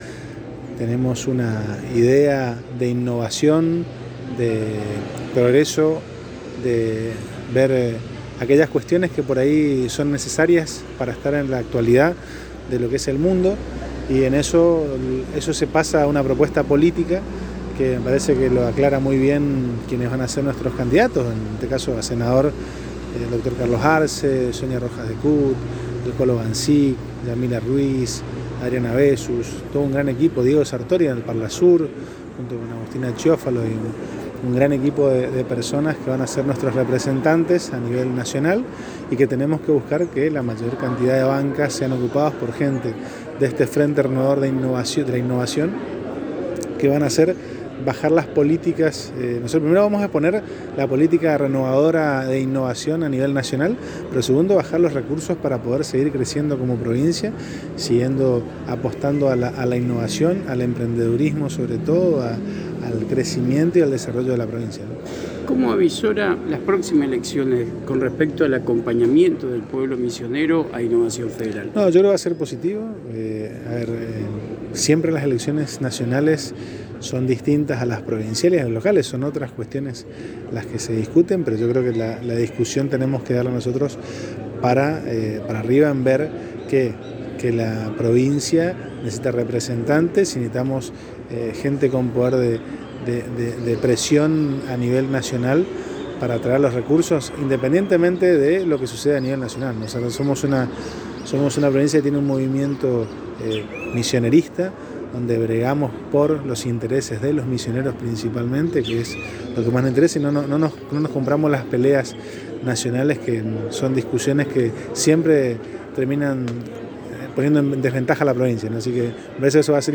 El Dr. Matias Sebely Intendente electo de Leandro N. Alem en diálogo exclusivo con la ANG señaló que Innovación Federal está expresión política del Frente Renovador conducido por Carlos Rovira va a aportar al contexto nacional aquellas políticas renovadoras que tanto bien le han hecho al Misionero.